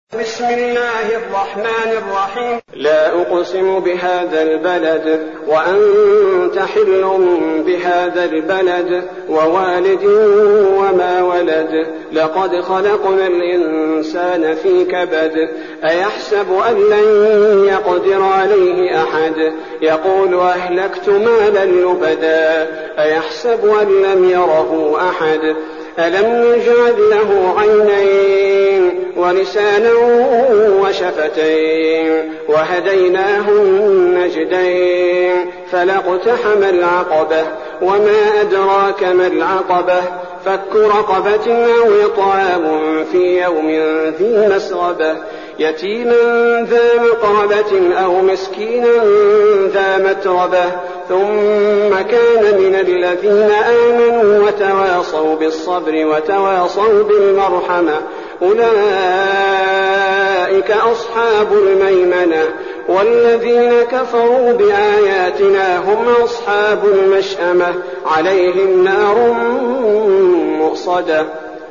المكان: المسجد النبوي الشيخ: فضيلة الشيخ عبدالباري الثبيتي فضيلة الشيخ عبدالباري الثبيتي البلد